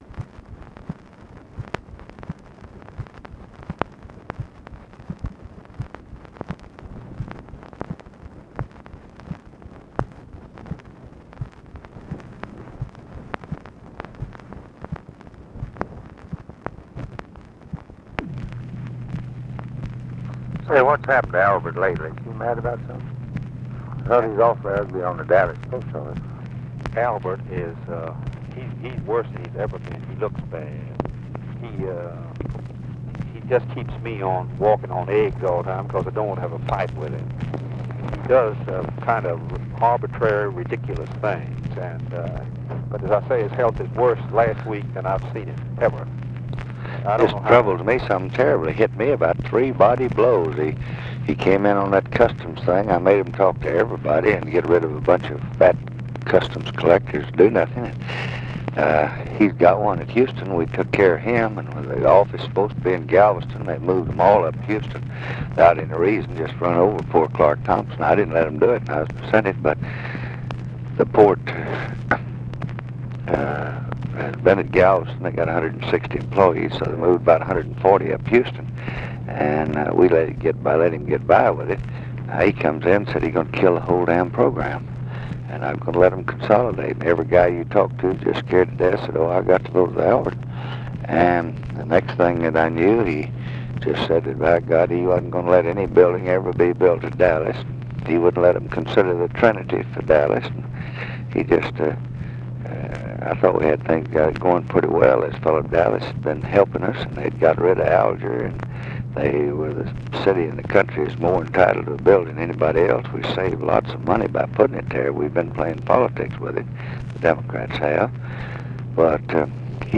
Conversation with GEORGE MAHON, May 10, 1965
Secret White House Tapes